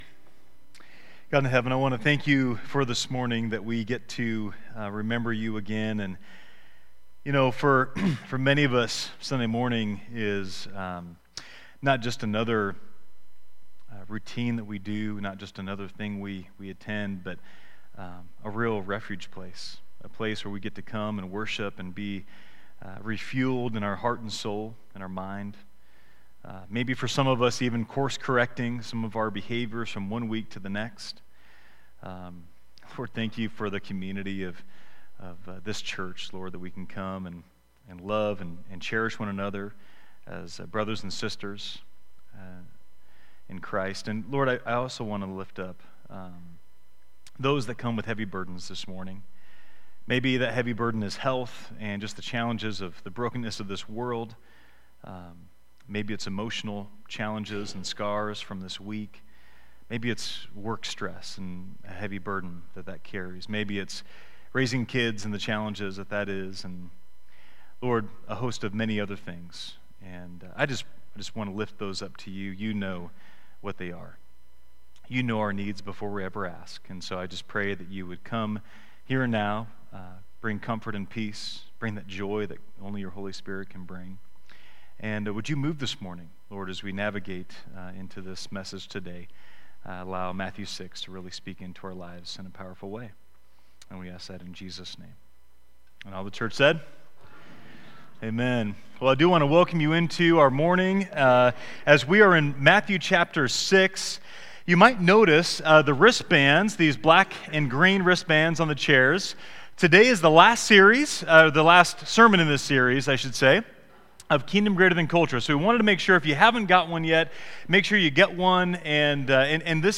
Download Download Reference Matthew 6:1-4 Sermon Notes 9.